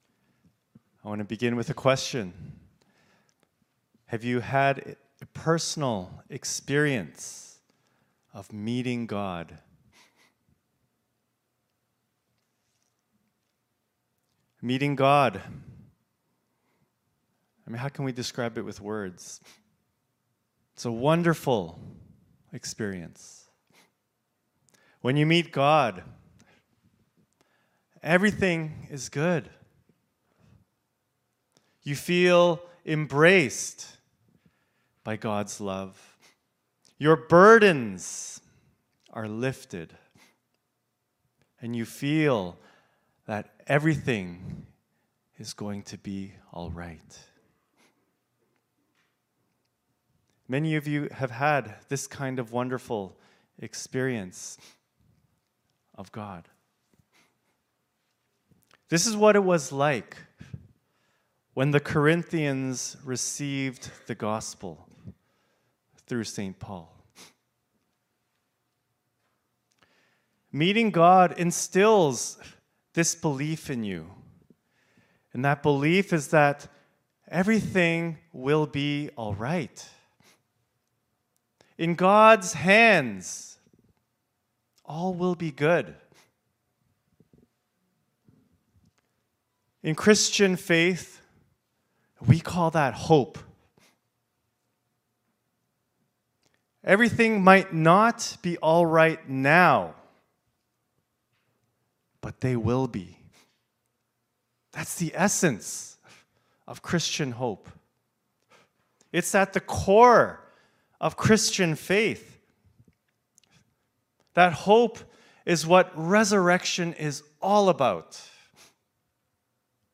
Scripture Passage 1 Corinthians 10:1-13 Worship Video Worship Audio Sermon Script Have you had a personal experience of meeting God?